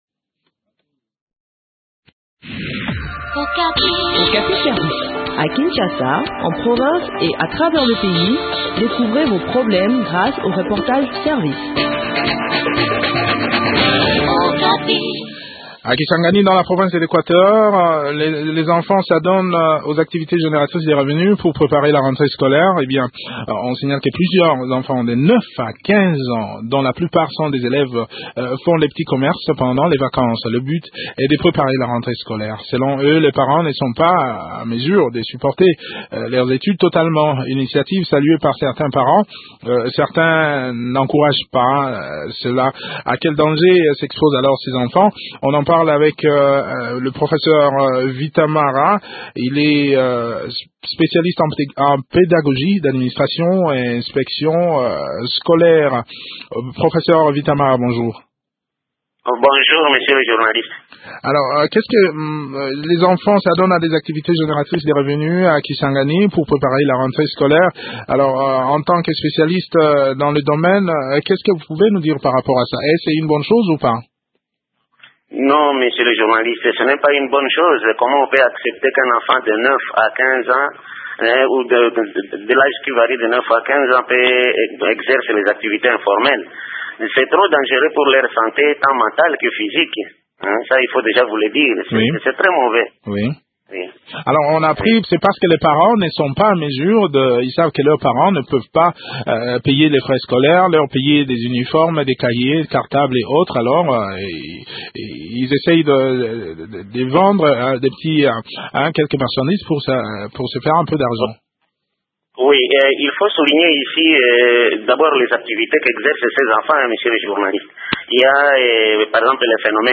Okapi service, Émissions / Leadership, Gouvernance, féminin